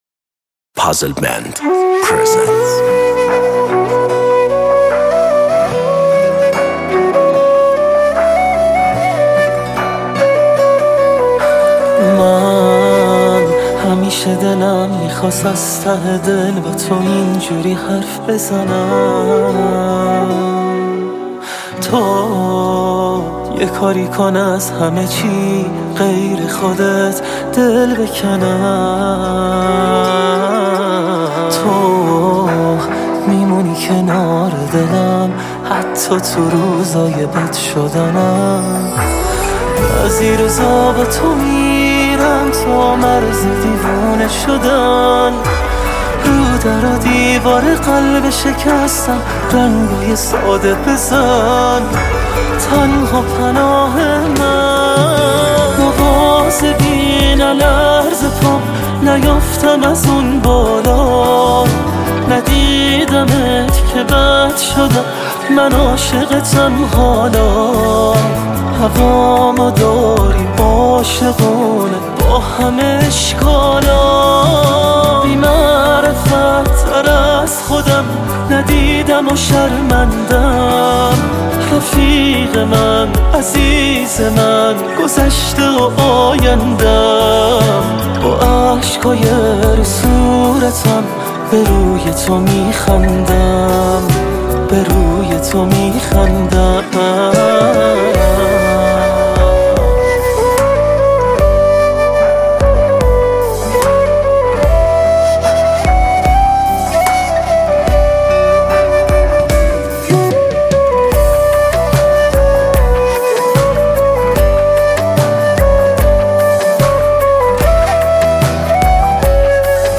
غمگین
پاپ